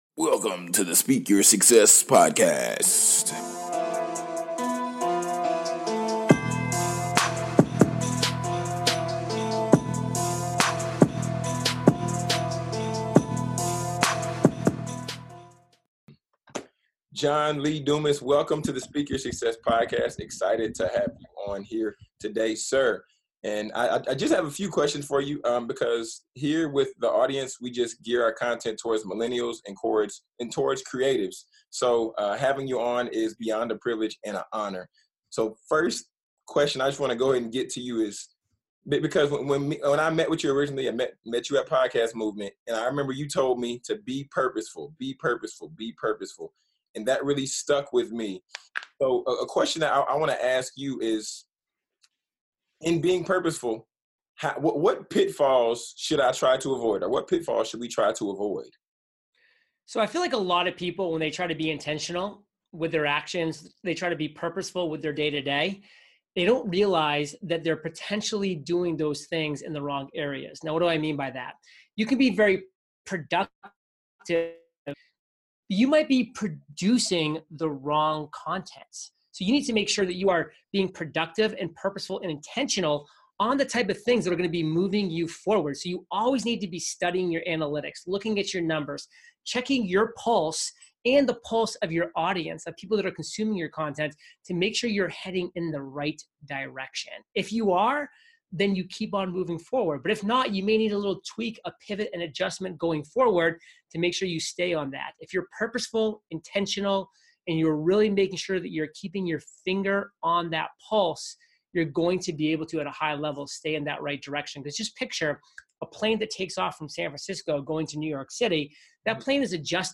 After connecting with John at Podcast Movement in Philadelphia I had to get him as a guest on the show.